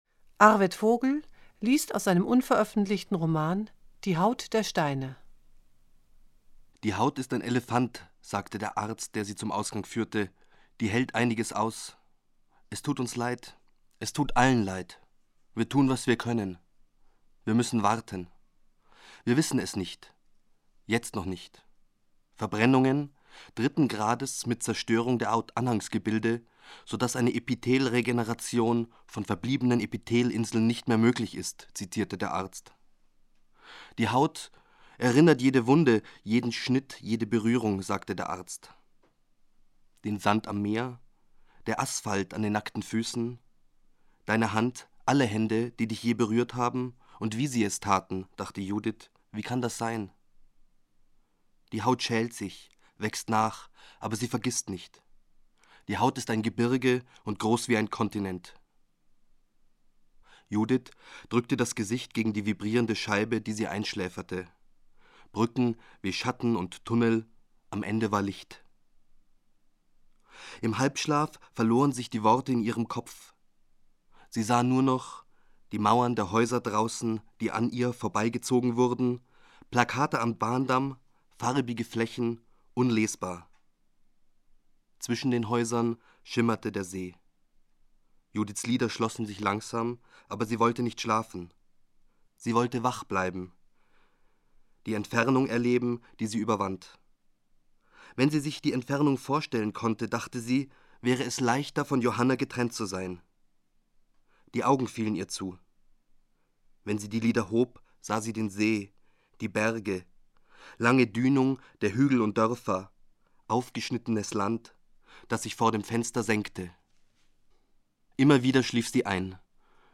Das Literaturtelefon-Archiv wird in der Monacensia im Hildebrandhaus aufbewahrt. Es umfasst 40 CDs, auf denen insgesamt 573 Lesungen enthalten sind.